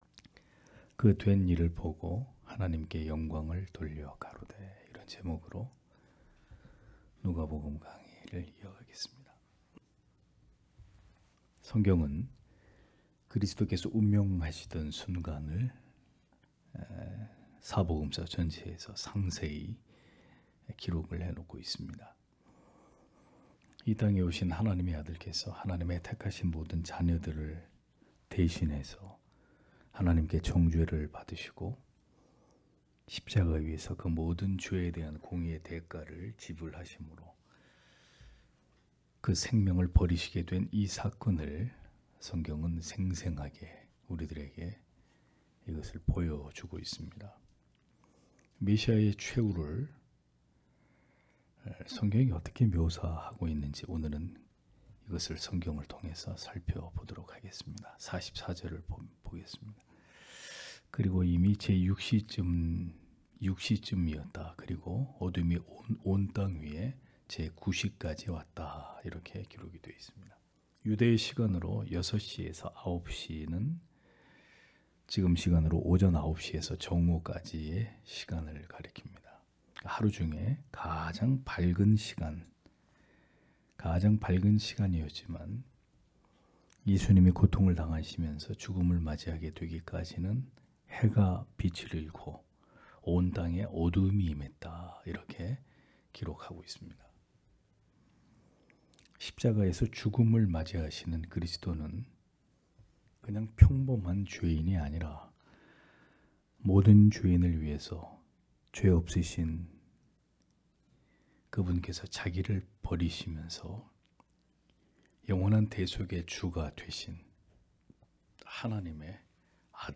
금요기도회 - [누가복음 강해 180] '그 된 일을 보고 하나님께 영광을 돌려가로되' (눅 23장 44- 49절)